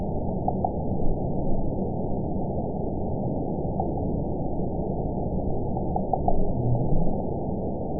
event 922227 date 12/28/24 time 12:33:42 GMT (5 months, 2 weeks ago) score 9.26 location TSS-AB03 detected by nrw target species NRW annotations +NRW Spectrogram: Frequency (kHz) vs. Time (s) audio not available .wav